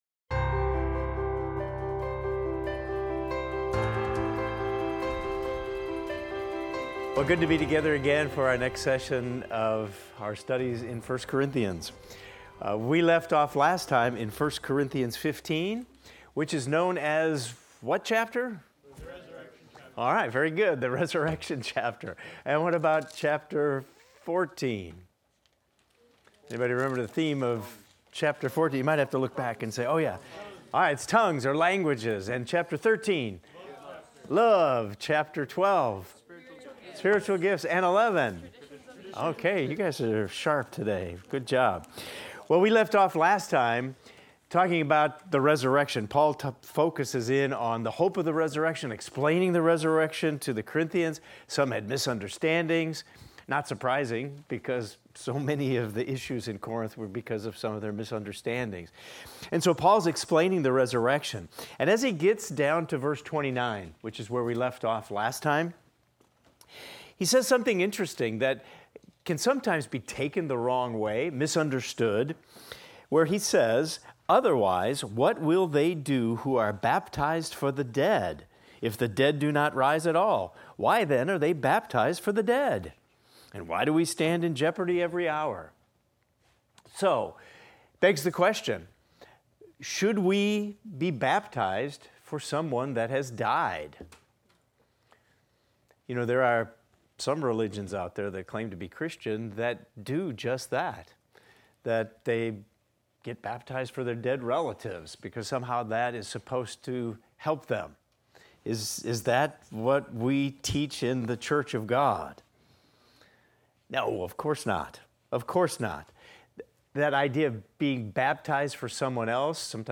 In this class we will discuss 1 Corinthians 15:29–58 and examine the following: Paul discusses the significance of the resurrection and its implications for believers.